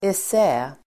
Uttal: [es'ä:]